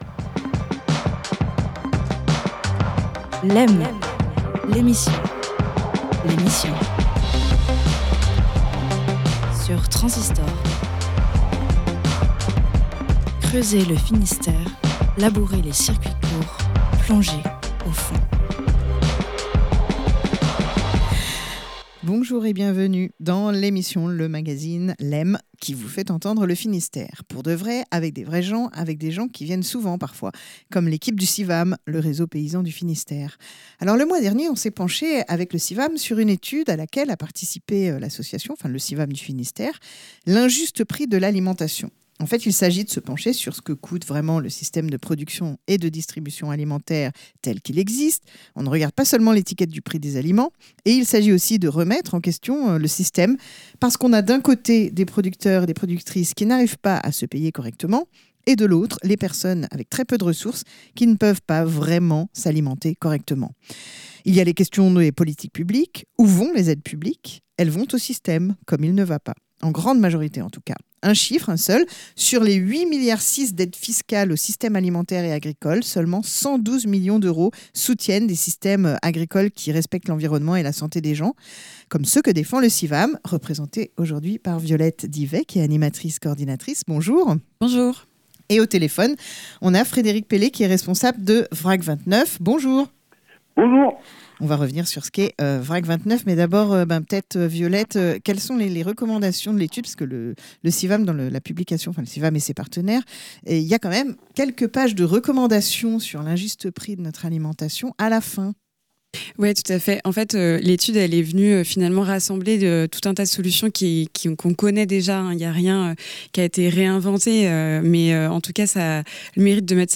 Une émission mensuelle de Lem, la quotidienne, réalisée en partenariat avec le réseau Civam du Finistère